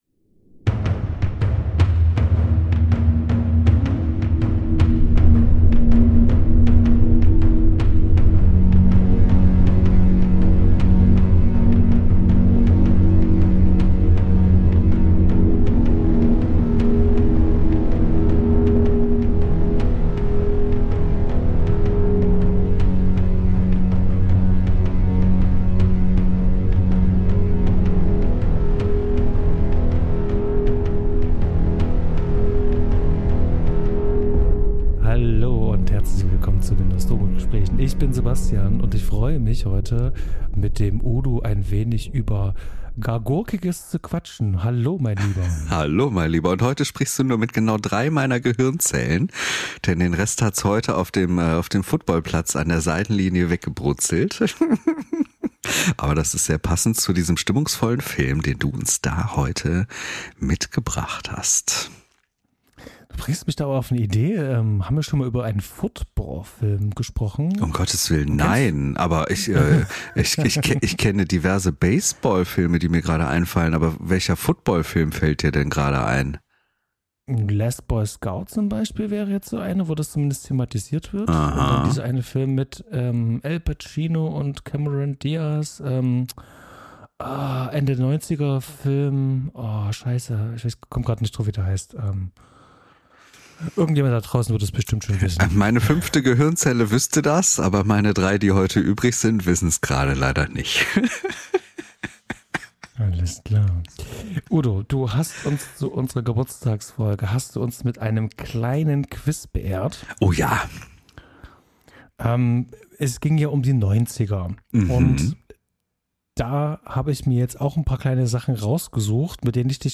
Filmbesprechung